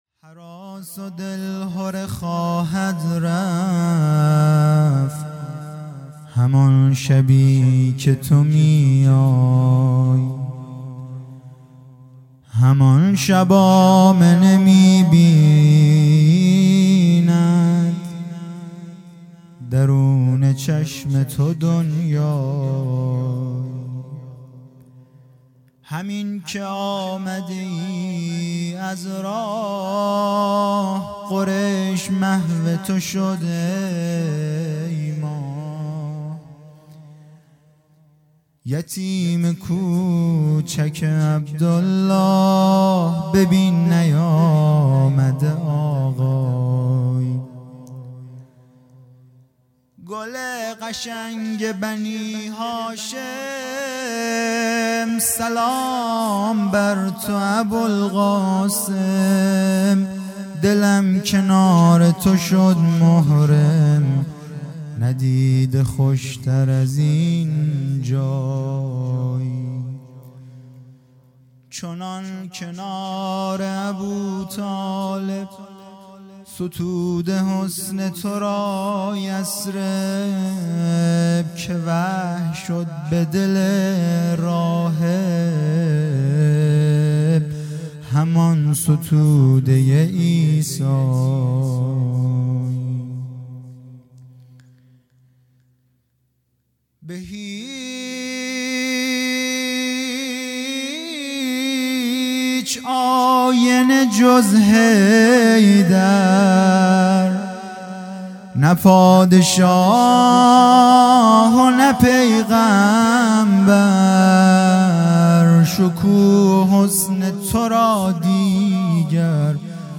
مدح
ولادت پیامبر (ص) و امام صادق (ع) | ۶ آذر ۱۳۹۷